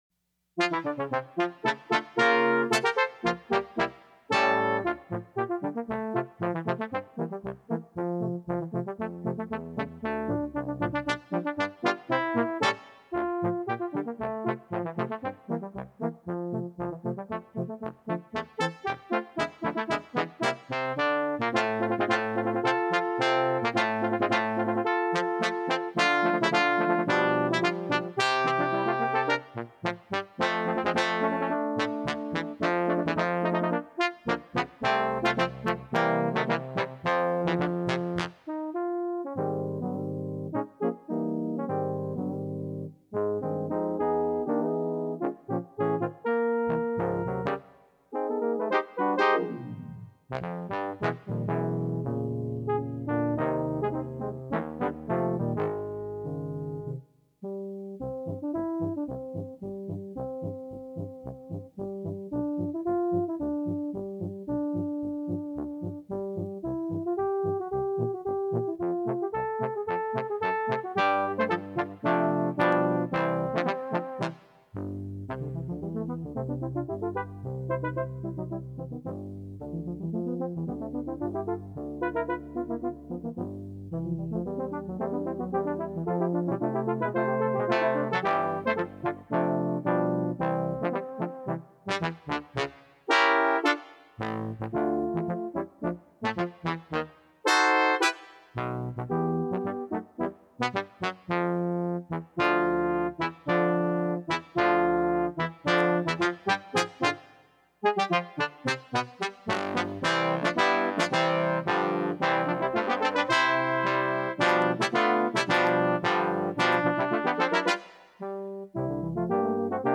in a jazz style!
jazz